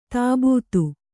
♪ tābūtu